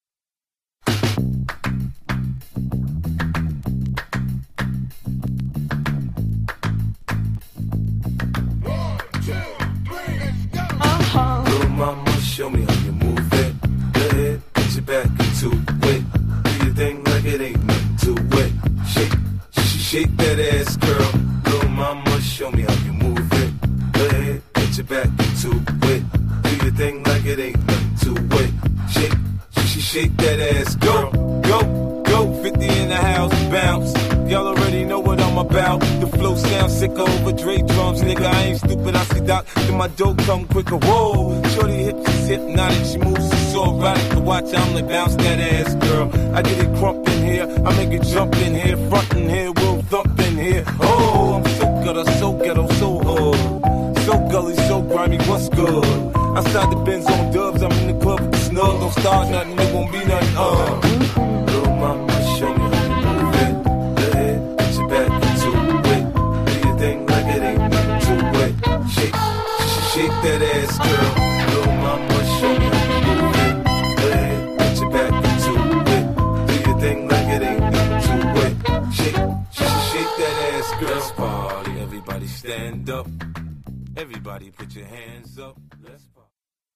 96 bpm